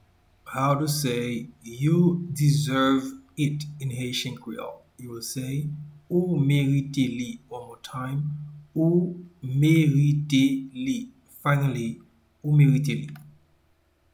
Pronunciation and Transcript: